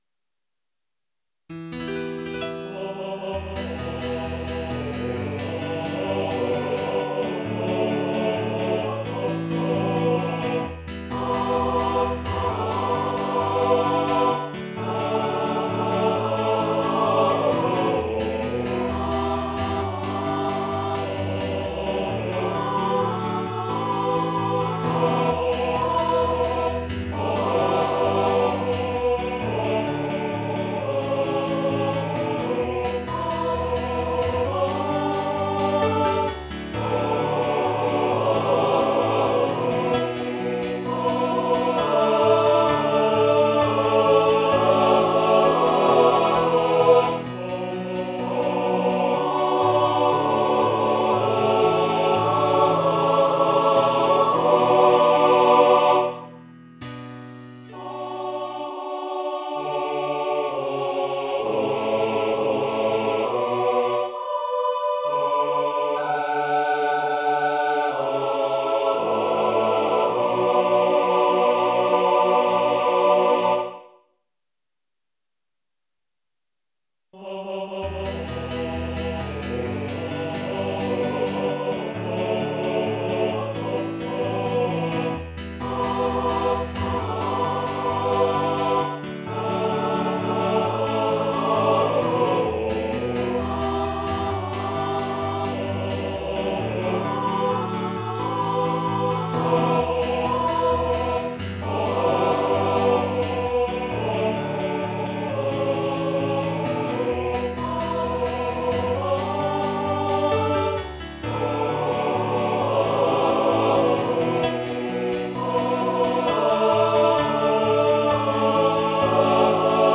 Tags: Piano, Choral